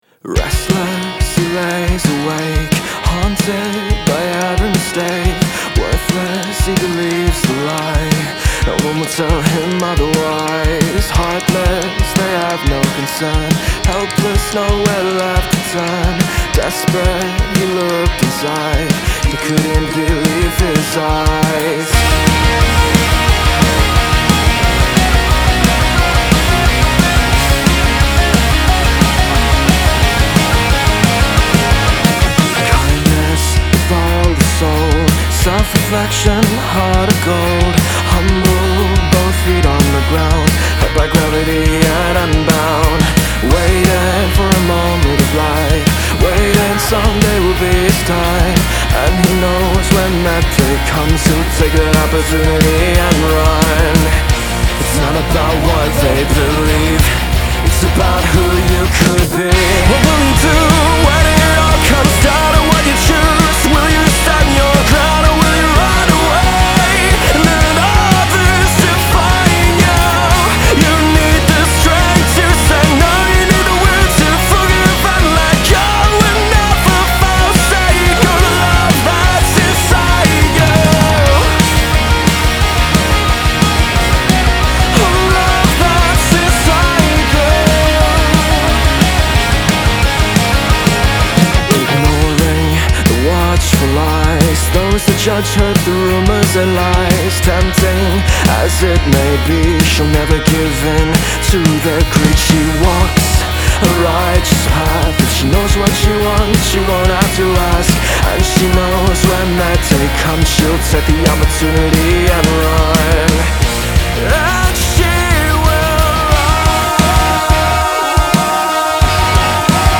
Rock alternativo
una producción dinámica llena de misterio y poder